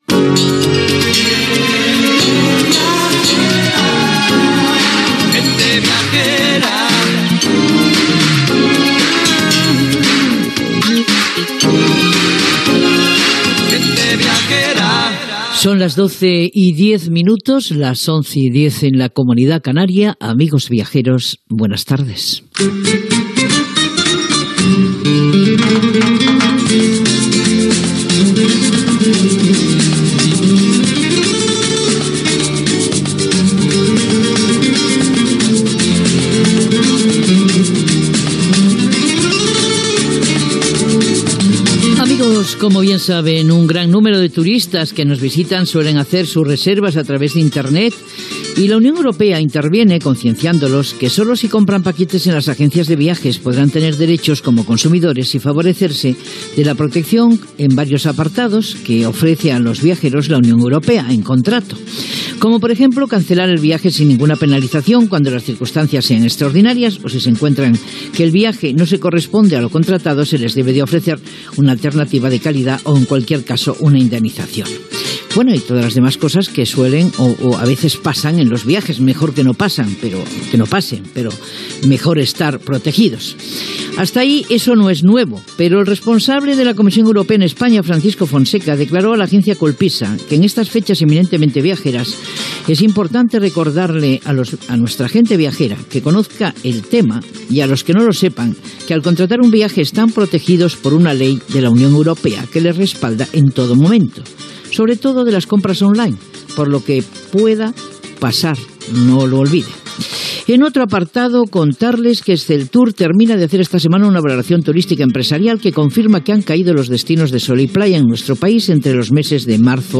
Divulgació
Fragment extret del "Banc de veus" del web Dones a les Ones